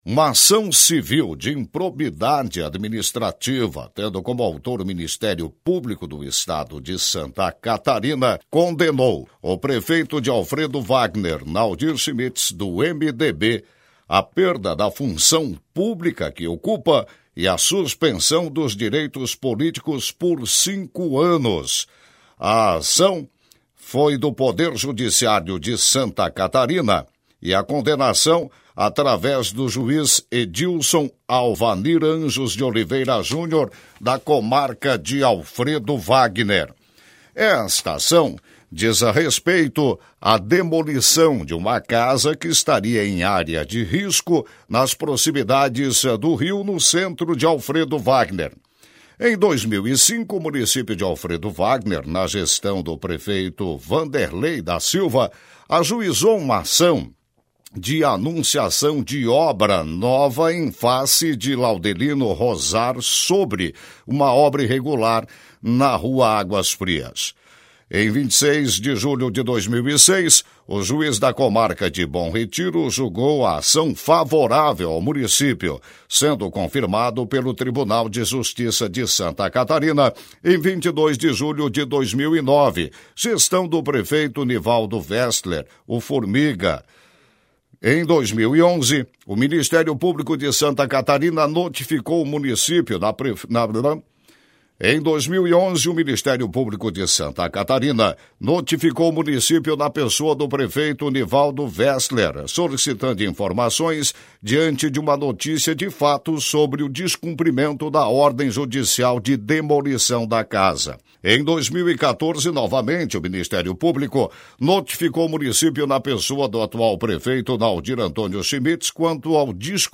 Na ocasião a Rádio Sintonia também apresentou, no final da matéria, declaração do Prefeito sobre a condenação.